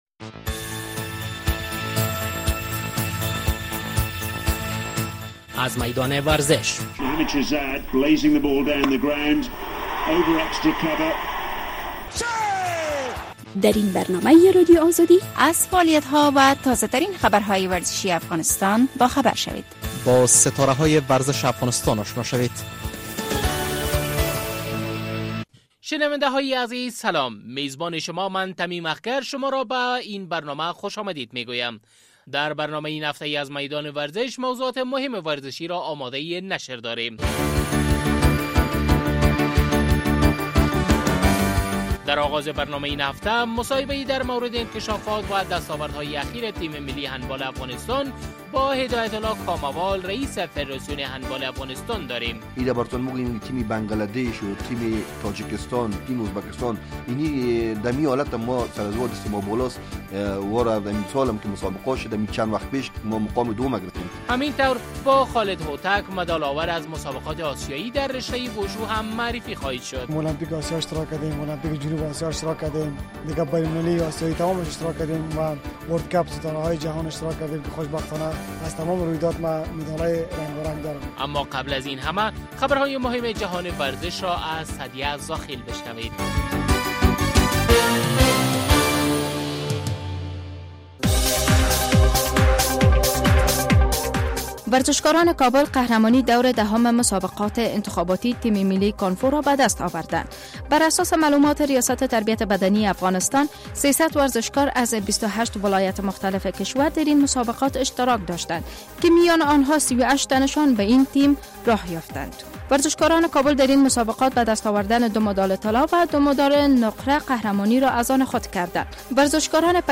در برنامه این هفته از میدان ورزش موضوعات مهم ورزشی را آماده نشر داریم. در آغاز برنامۀ این هفته مصاحبه در ...